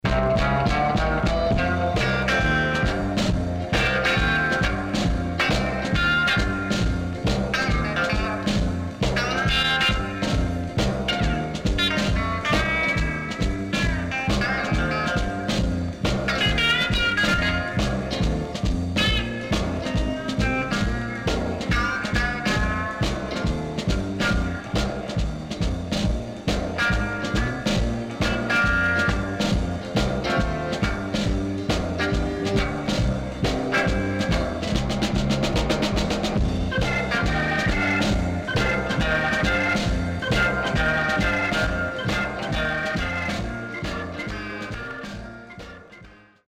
64年.Funky Soul Vocal & Organ Inst.W-Side Good.305
SIDE A:所々プチノイズ入ります。